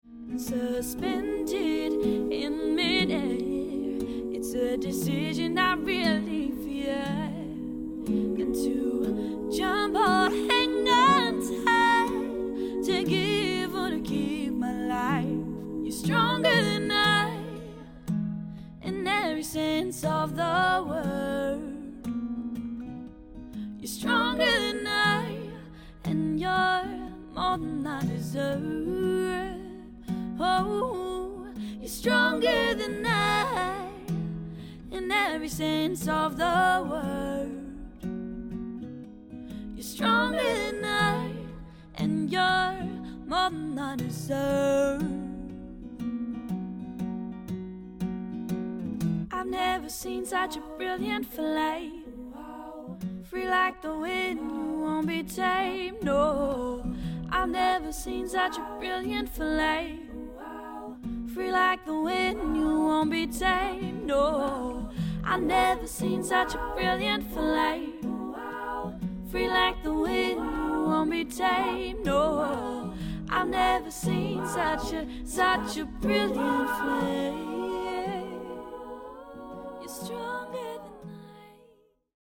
Tracking Demo Mix